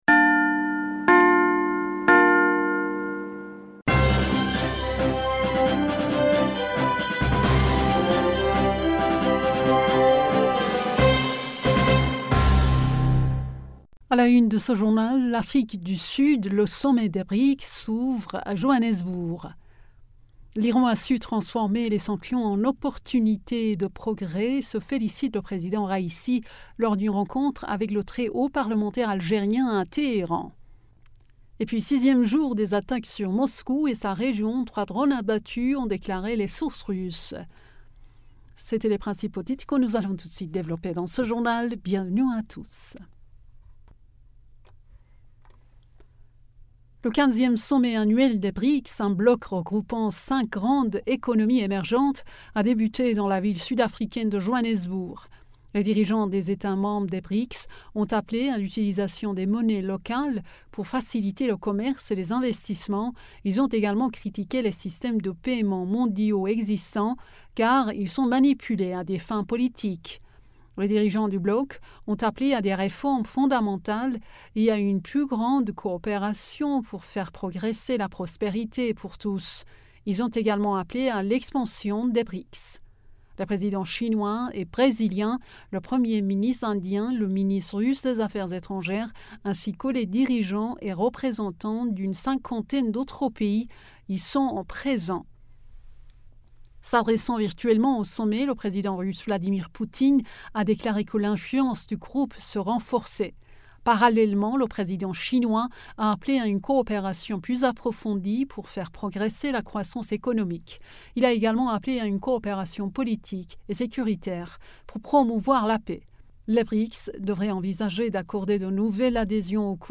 Bulletin d'information du 23 Aout 2023